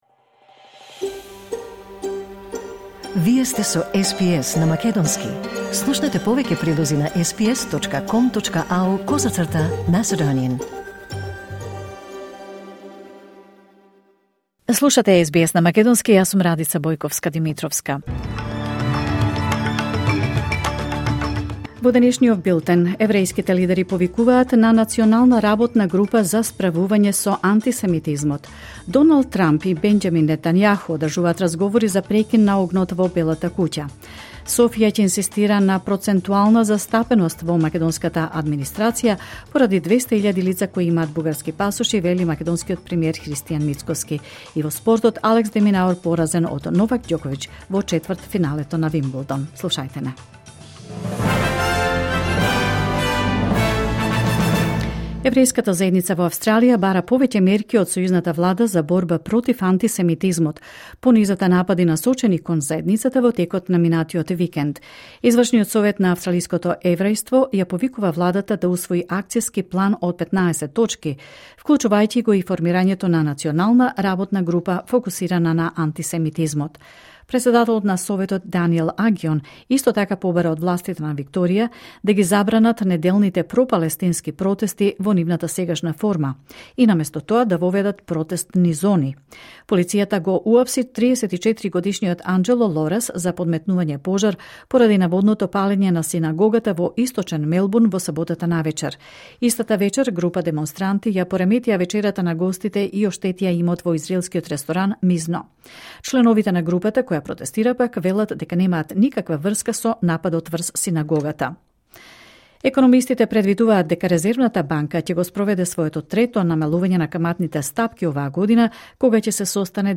Вести на СБС на македонски 8 јули 2025